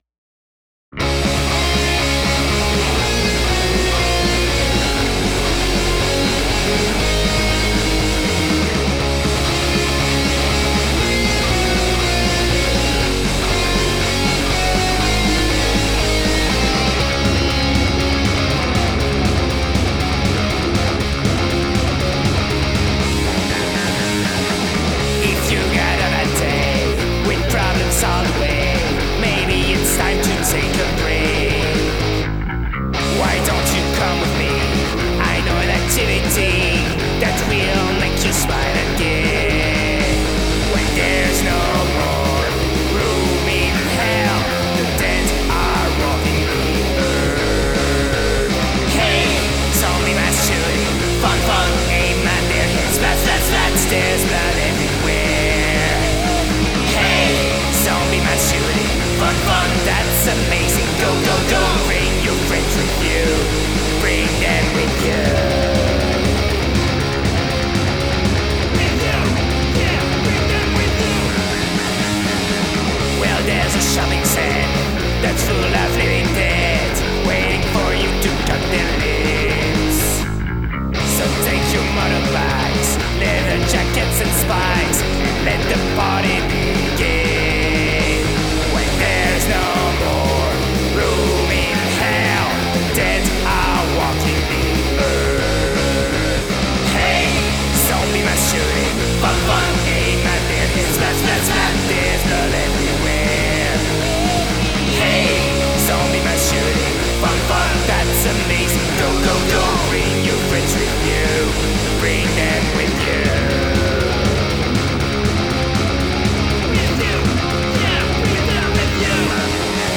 Rock > Punk